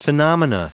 Prononciation du mot phenomena en anglais (fichier audio)
Prononciation du mot : phenomena